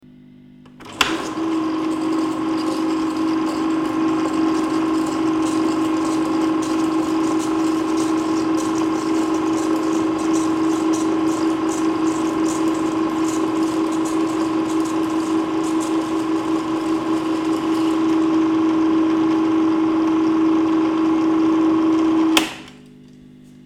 Super 8 Movie Projector - Reverse